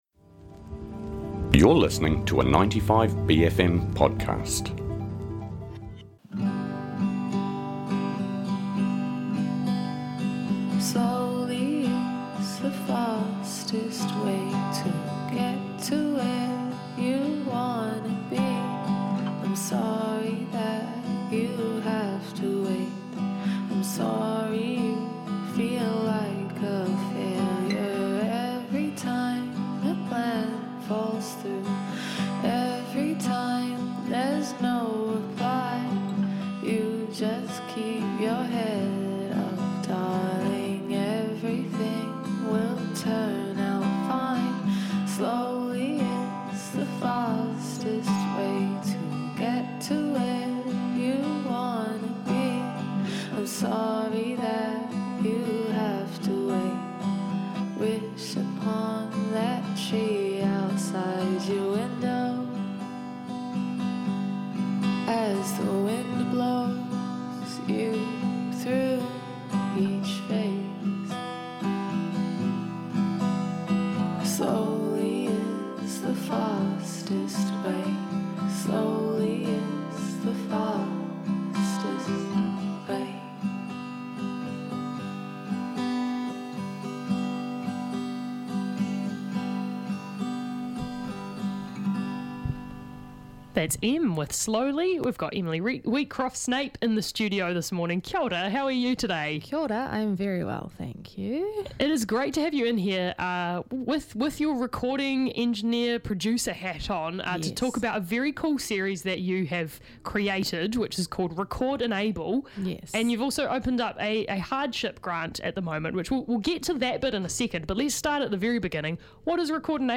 Guest Interviews